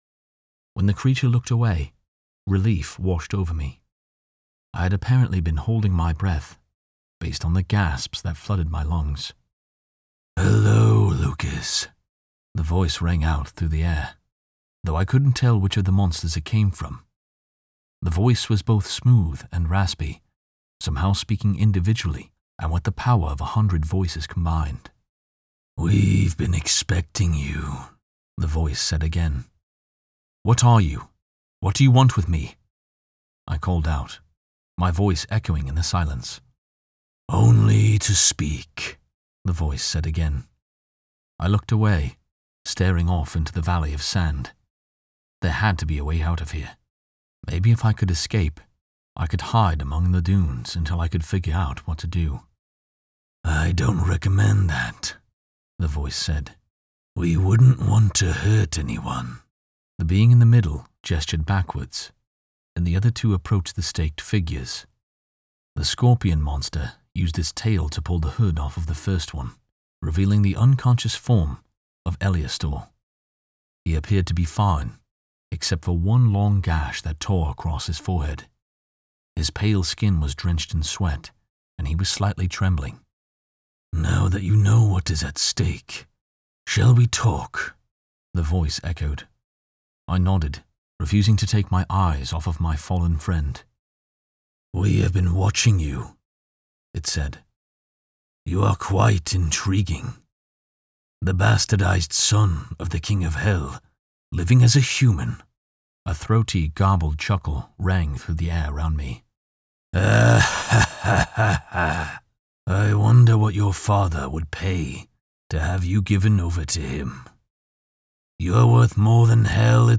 Specializing in Audiobooks and Narration, my full cast of voices can bring diverse identities to the characters and setting of your next story!
Audiobook and Narration
Demons and Monsters from ‘Corrupted Blood’